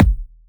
edm-kick-05.wav